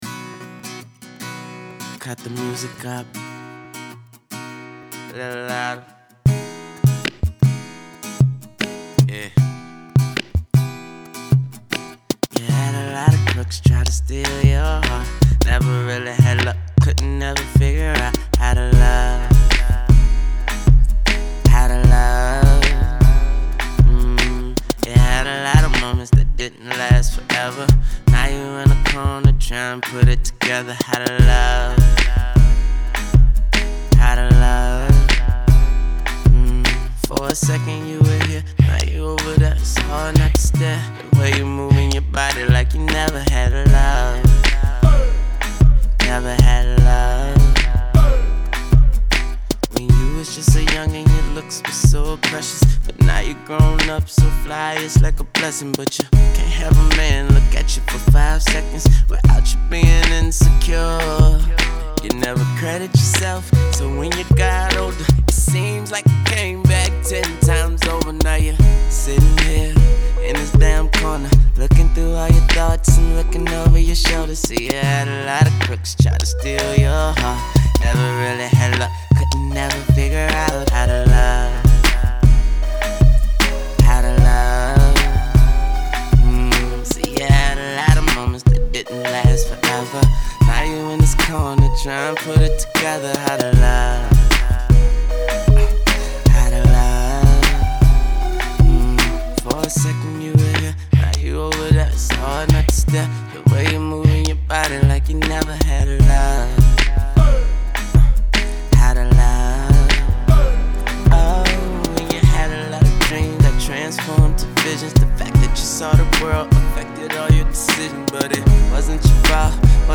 Thankfully, the song abruptly cuts the sequence short.